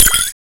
SPOON.WAV